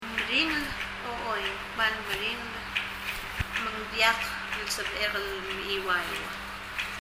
mechiuaiu [mɛ ? iwaiwu] sleep, 眠る